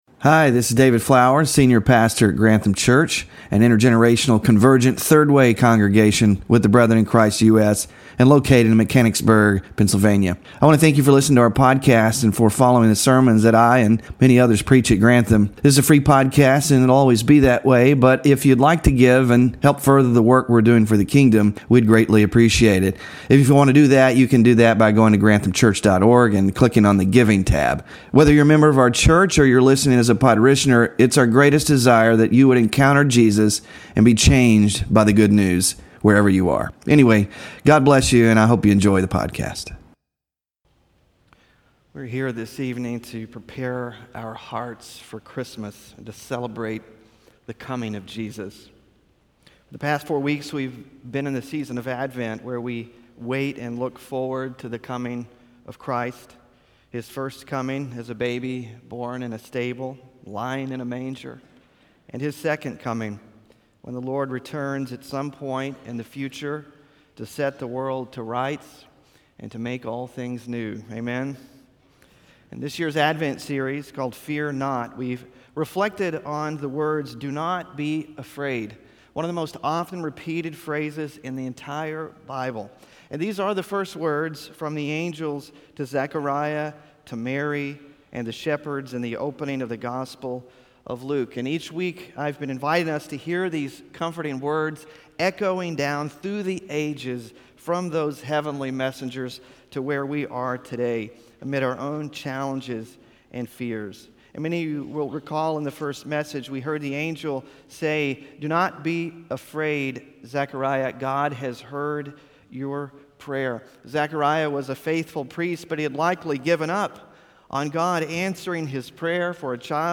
In this Christmas Eve message